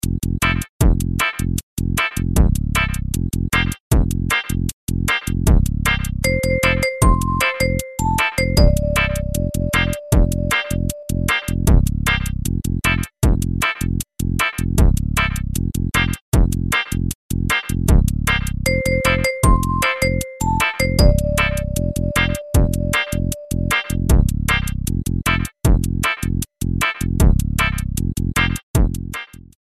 Trimmed, added fadeout
Fair use music sample